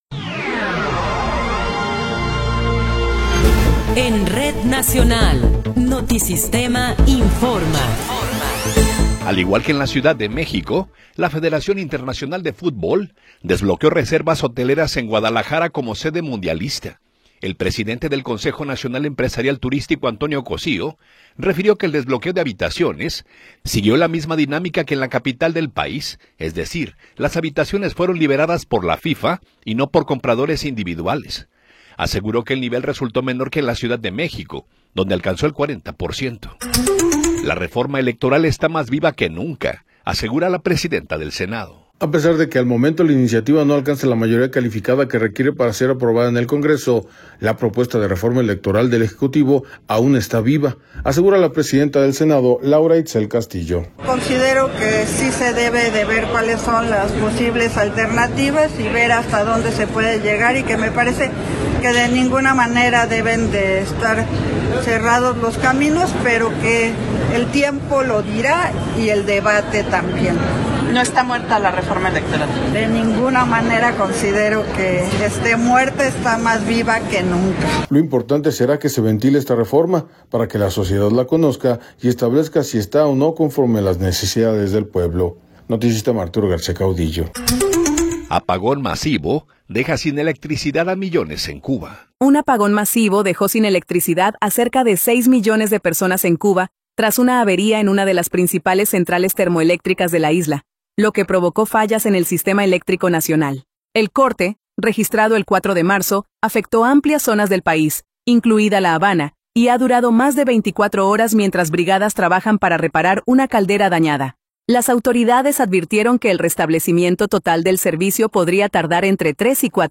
Noticiero 19 hrs. – 5 de Marzo de 2026
Resumen informativo Notisistema, la mejor y más completa información cada hora en la hora.